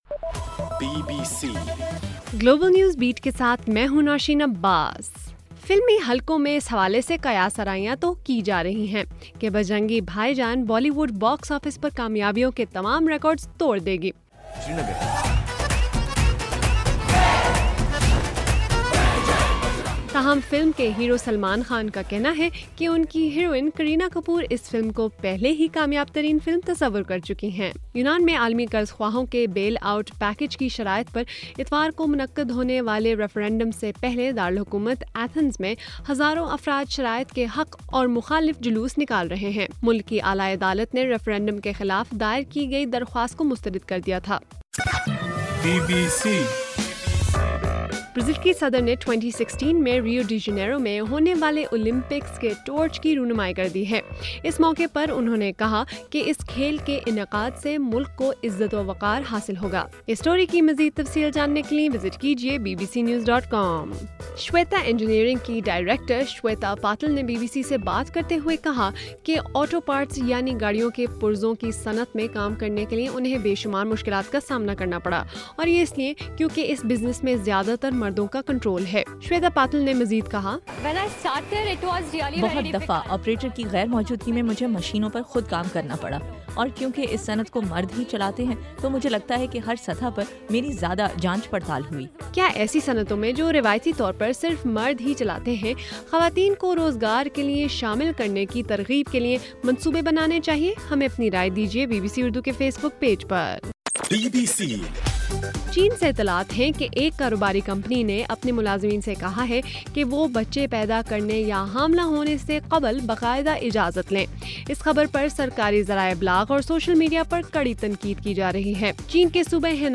جولائی 4: رات 11 بجے کا گلوبل نیوز بیٹ بُلیٹن